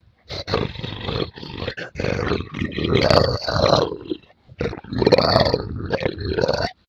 angry-noises-2.ogg